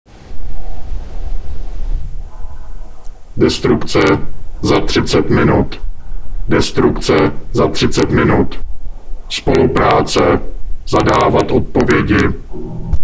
mluvené pokyny 3 ks soubory: